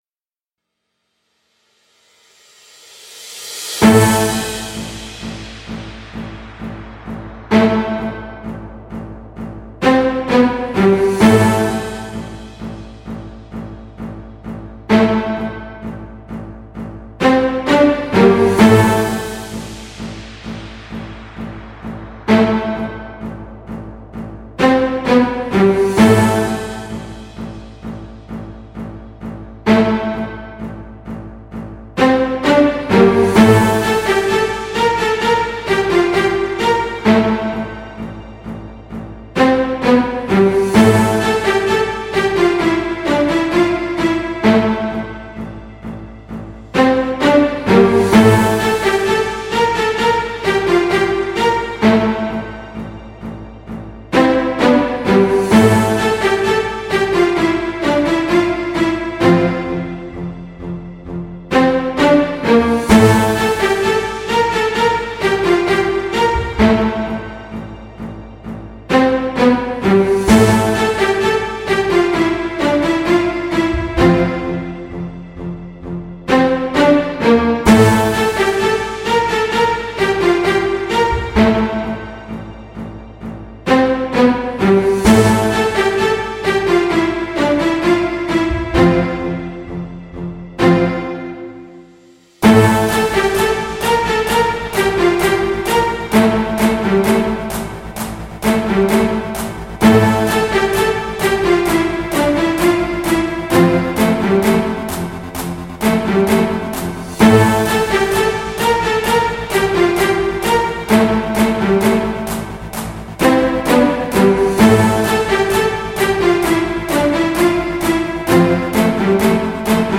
Žánr: Jazz/Blues
Trošku symfonický a orchestrálny experiment.